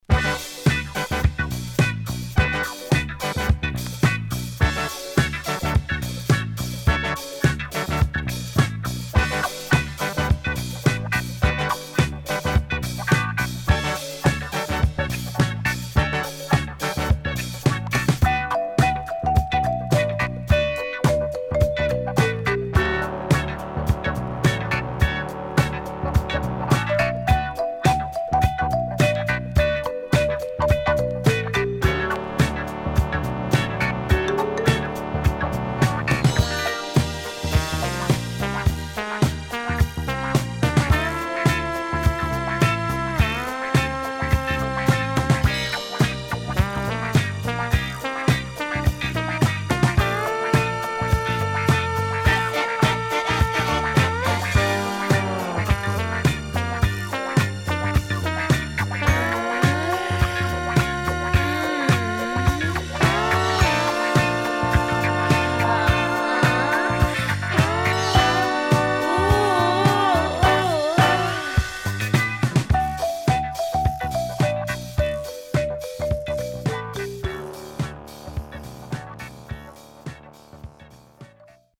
76年Dance Classic.W-Side Good.115
SIDE A:盤質は良好です。